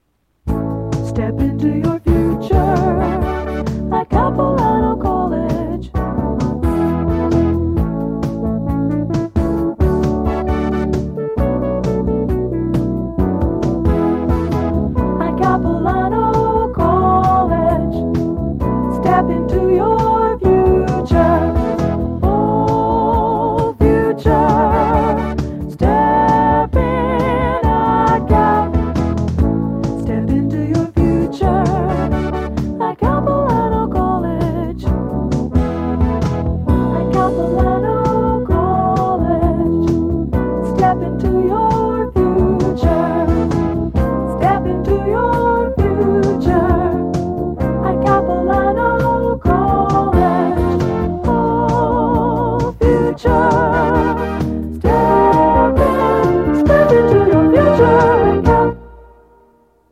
audio cassette